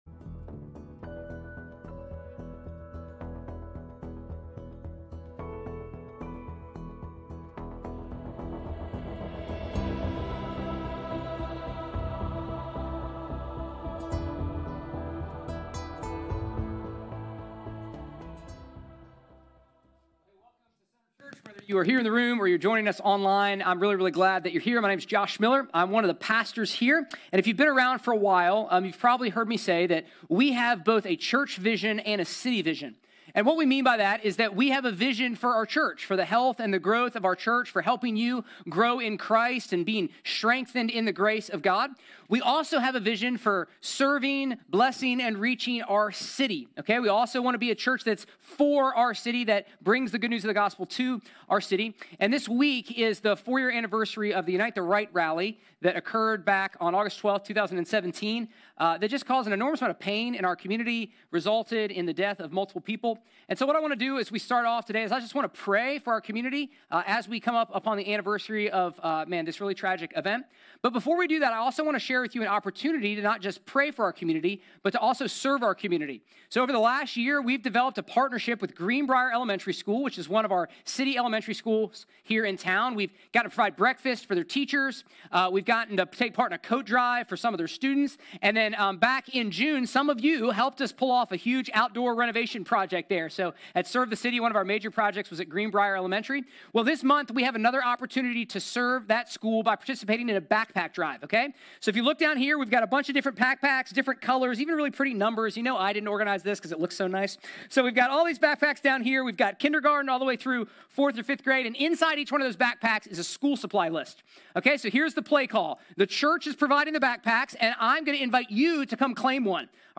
A message from the series "The Gospel of Mark."